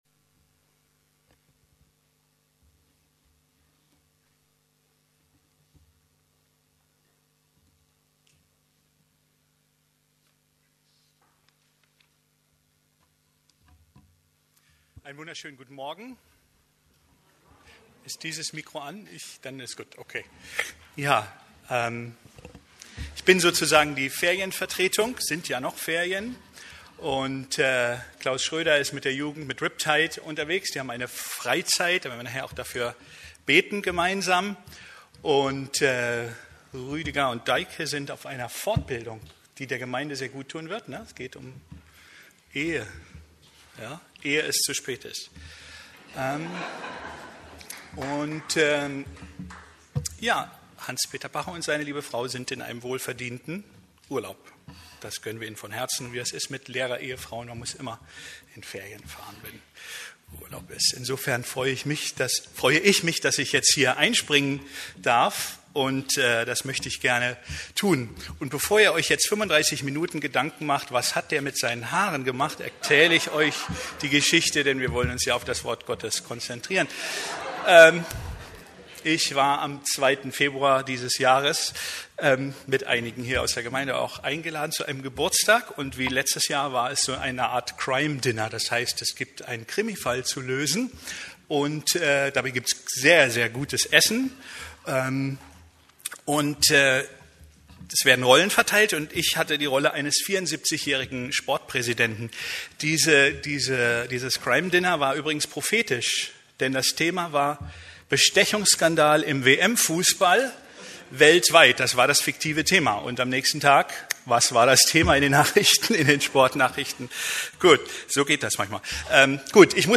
Love and Glory (Liebe und Kraft) ~ Predigten der LUKAS GEMEINDE Podcast